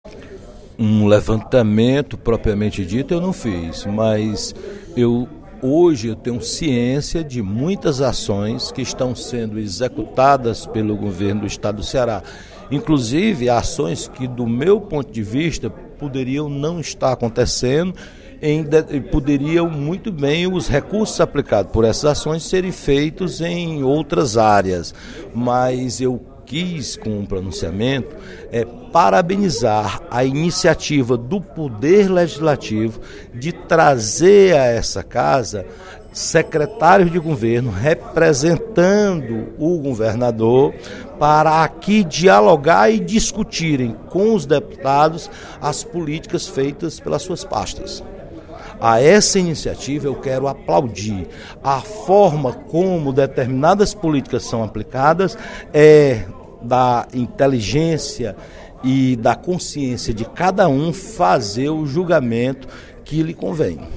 O deputado Roberto Mesquita (PV) parabenizou, em pronunciamento no primeiro expediente da sessão plenária desta sexta-feira (28/06), o que considera um grande avanço: a presença de secretários na Assembleia Legislativa para expor realizações das pastas que dirigem.
Em aparte, o deputado Heitor Férrer (PDT) considerou que os secretários não vêm para atender o chamamento dos parlamentares.